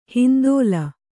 ♪ hindōla